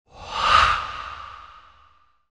supernatural-breath-sound